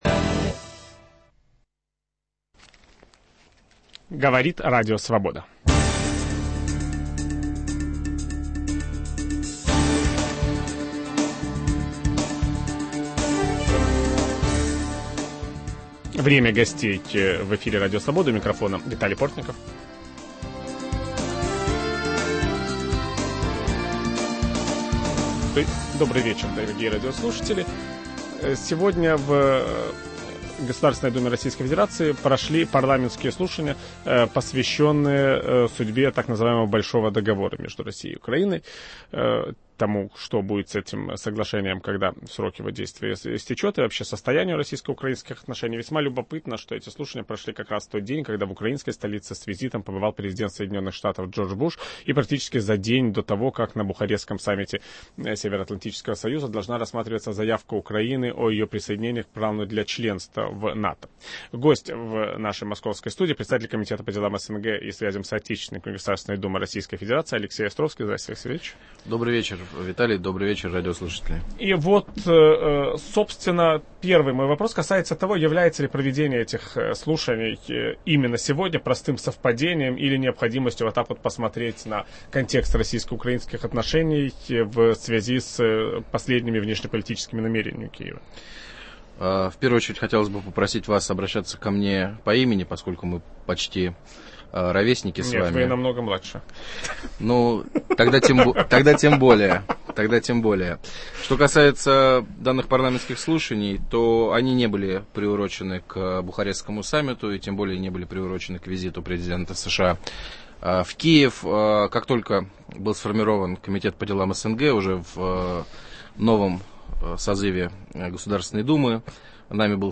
В день пребывания президента США Джорджа Буша в Киеве депутаты российской Государственной Думы обсуждали судьбу так называемого "большого" Договора между Россией и Украиной. О слушаниях, прошедших в нижней палате российского парламента и о будущем российско-украинского диалога ведущий программы Виталий Портников беседует с председателем комитета по делам СНГ и связям с соотечественниками Государственной Думы России Алексеем Островским.